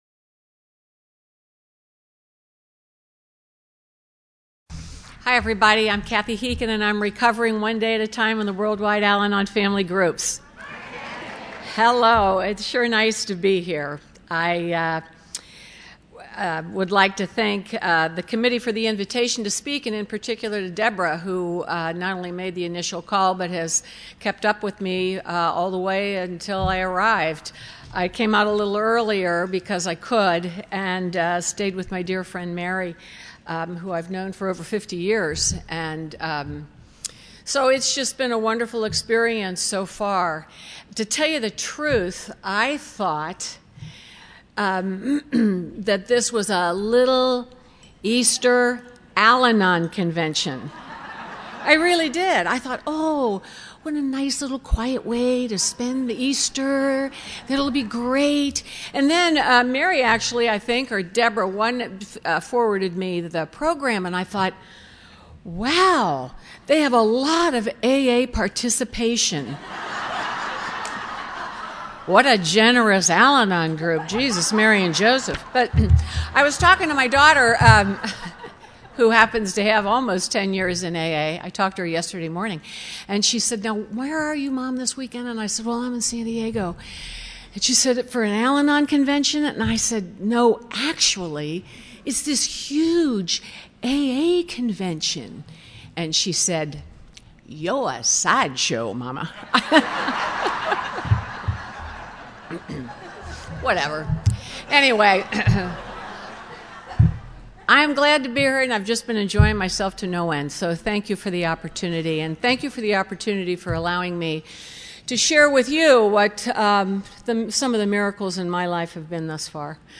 Encore Audio Archives - 12 Step Recovery San Diego Spring Roundup 2012